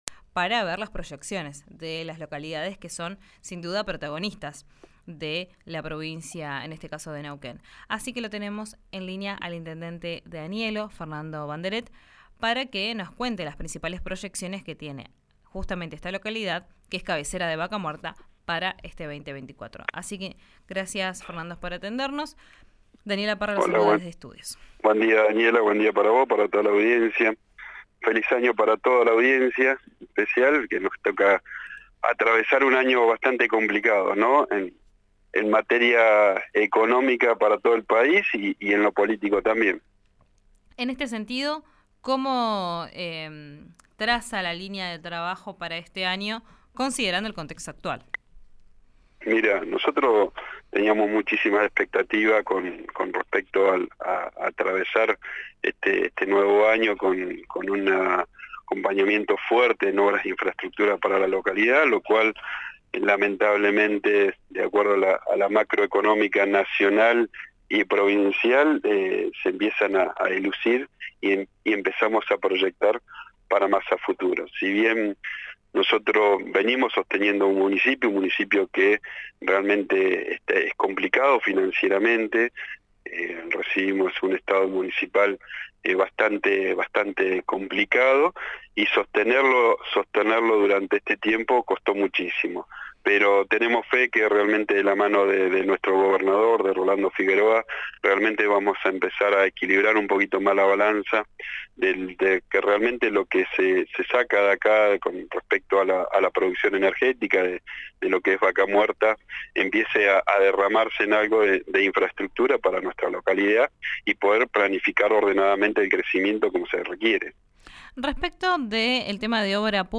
El intendente Fernando Banderet sostuvo que es una de las principales problemáticas en la localidad cabecera de Vaca Muerta.